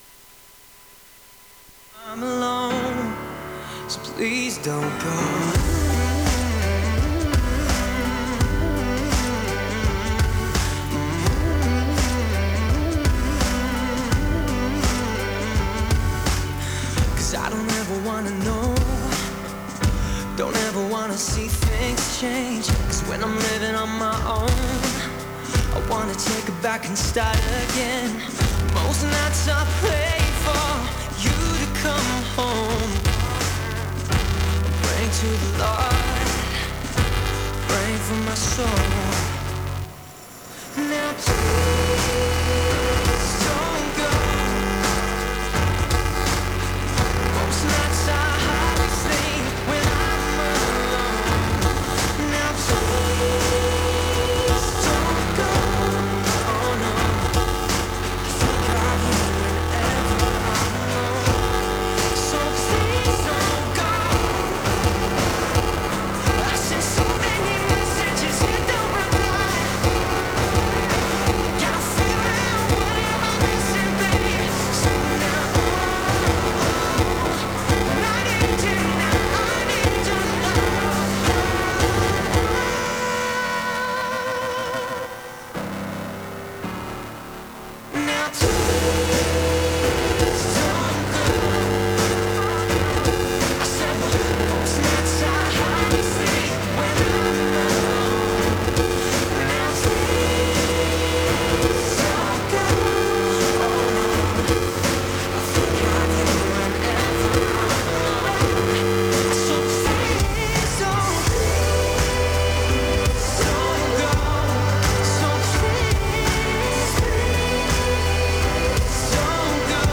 LAUNCHXL-CC1352P: The problem of noise slowly getting louder and disappearing suddenly
Phenomenon description: after the audio transmitted by RF is normally played for a period of time, there will be a small noise suddenly, and then the noise will gradually increase, and it will suddenly return to normal after a period of time. Then it slowly becomes noisy, alternating between normal and abnormal.